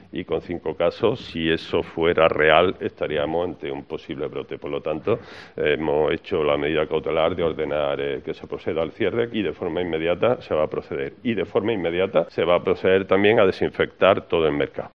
Escucha a Antonio Álvarez, delegado de Comercio